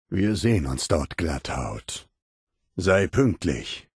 Der innere Ghul: Audiodialoge